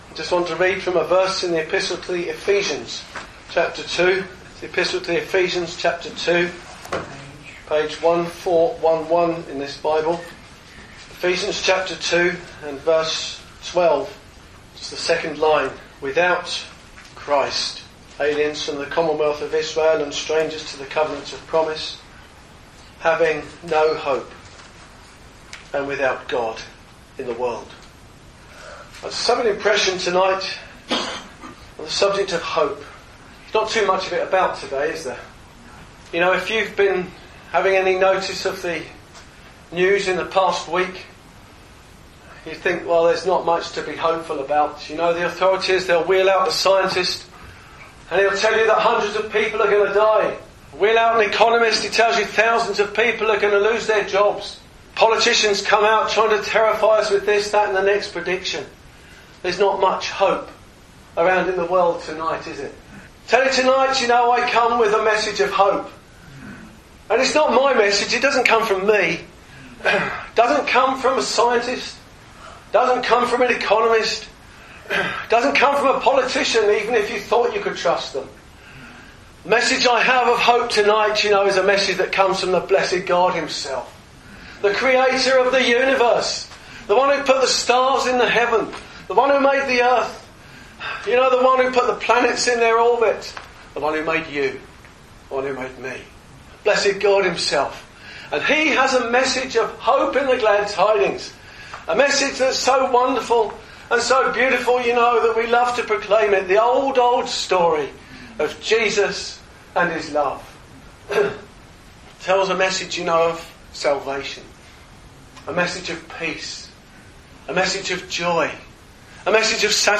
Listen to this Gospel preaching and discover how you can receive HOPE by accepting the Lord Jesus as your personal saviour.